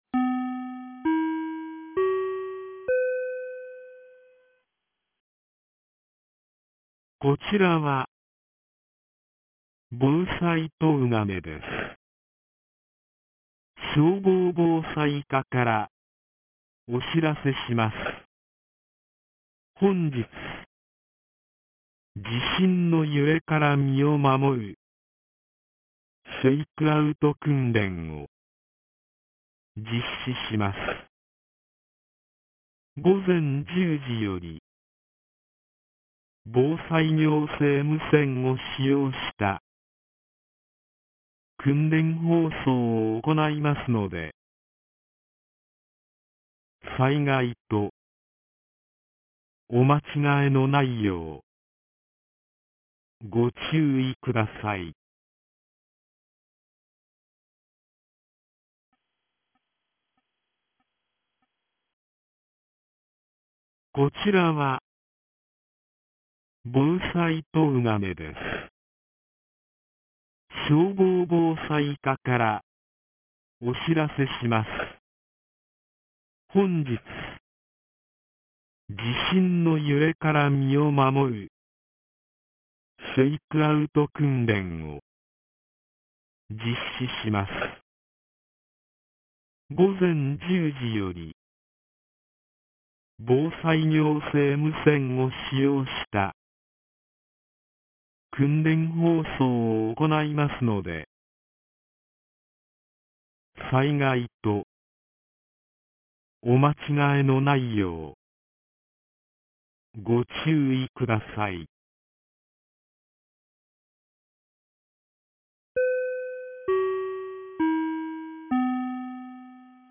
2023年09月01日 09時32分に、東金市より防災行政無線の放送を行いました。